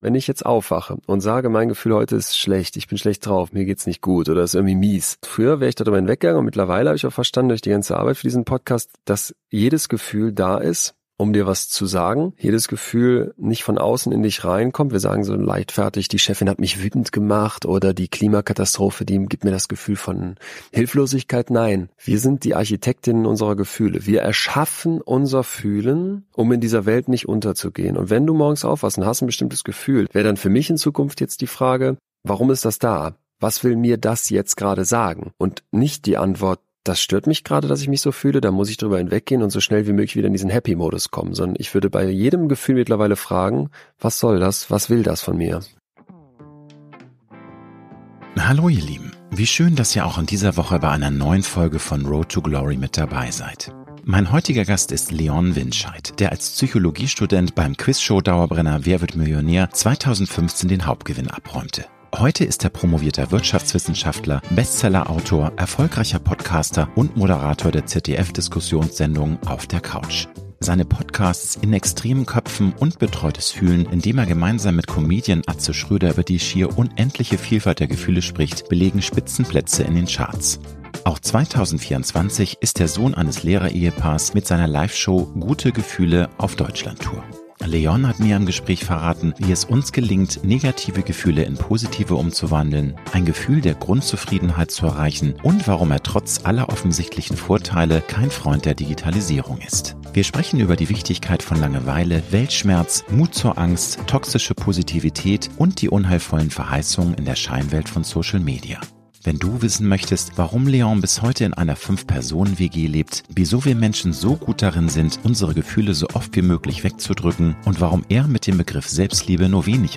Beschreibung vor 2 Jahren Mein heutiger Gast ist Leon Windscheid, der als Psychologie-Student beim Quizshow-Dauerbrenner „Wer wird Millionär“ 2015 den Hauptgewinn abräumte.